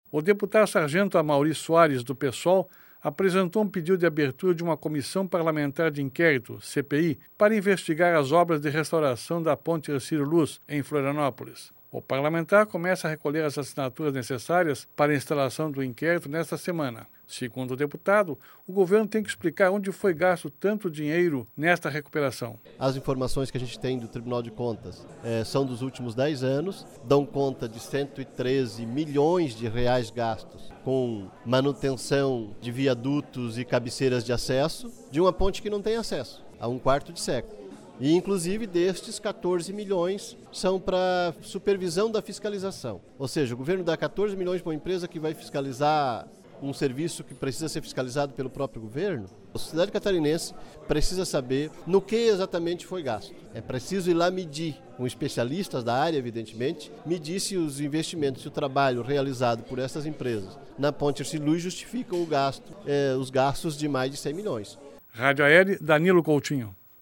Durante a sessão ordinária desta quarta-feira (19), o deputado Sargento Amauri Soares (PSOL) apresentou pedido de abertura de uma Comissão Parlamentar de Inquérito (CPI) para investigar as obras de restauração da Ponte Hercílio Luz, em Florianópolis.